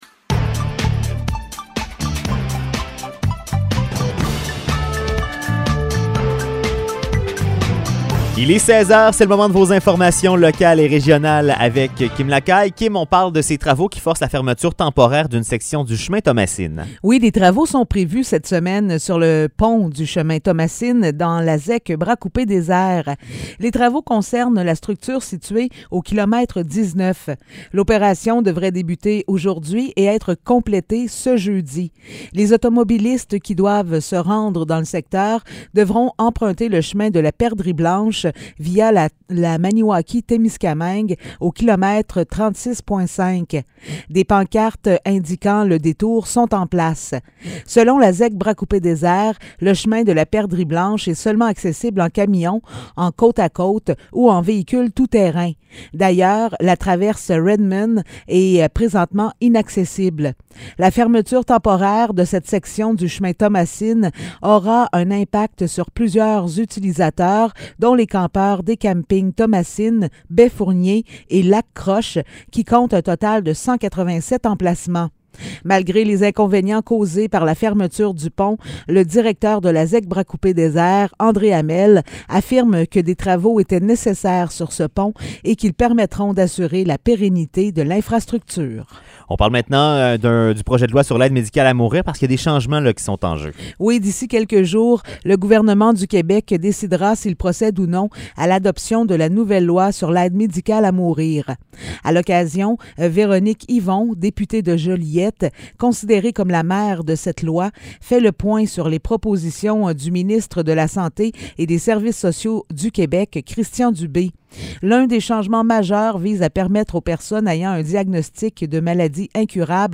Nouvelles locales - 6 juin 2022 - 16 h